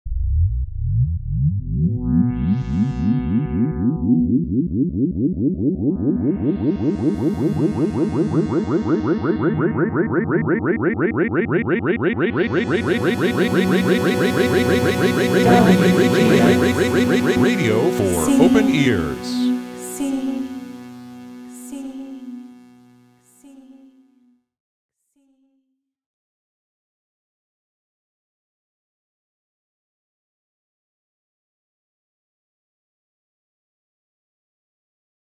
Open Call for Wave Farm's WGXC Station Soundmark / Audio Earworm "Radio for Open Ears": Feb 08, 2019 - Mar 06, 2019